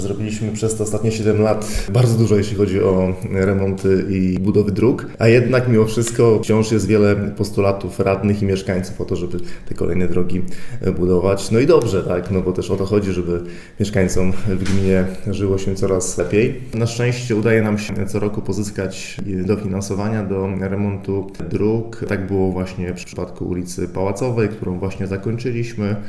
– Dążymy to tego, aby w mieście i gminie jeździło się wygodnie i bezpiecznie. Ruszamy z kolejnymi pracami drogowymi – mówi burmistrz Iłowej Paweł Lichtański: